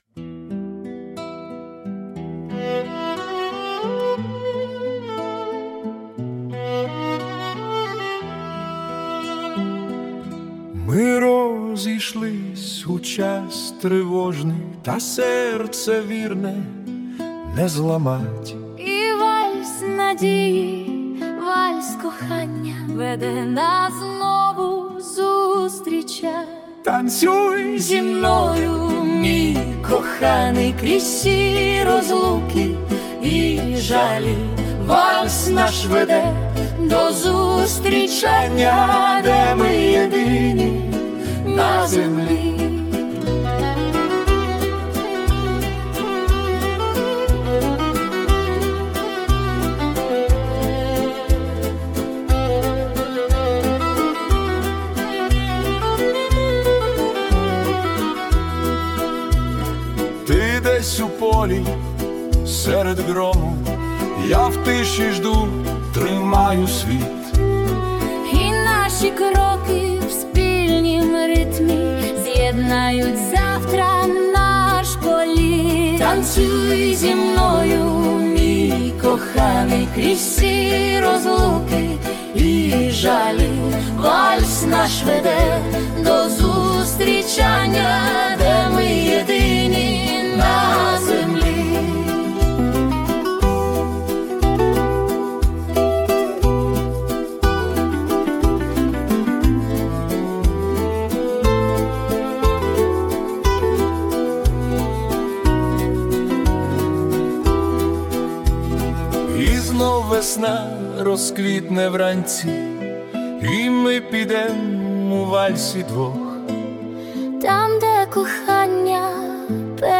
🎵 Жанр: Romantic Waltz